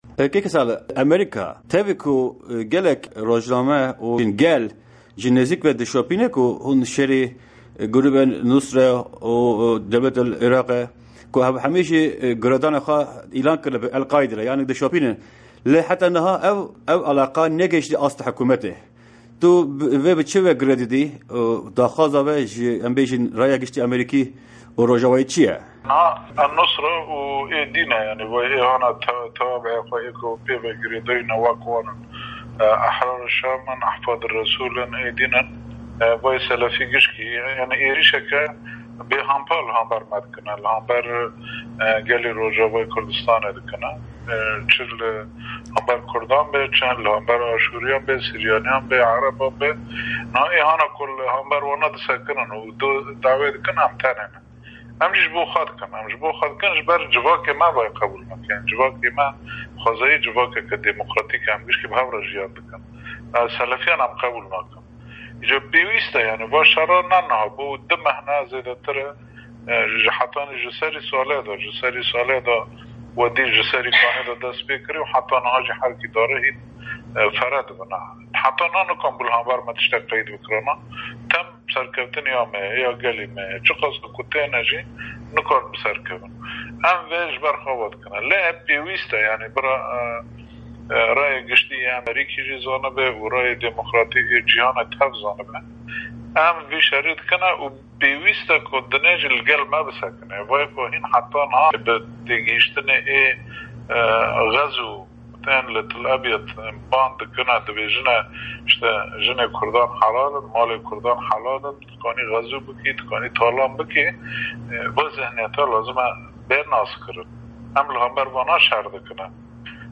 Hevserokê PYD Salih Mislim, di hevpeyvîneke taybet de sîyaseta Amerîka ya ser Kurdistana Sûrîyê şîrove dike.